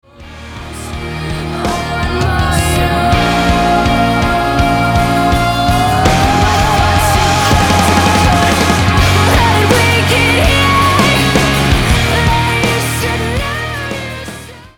Из какого фильма данный саундтрек?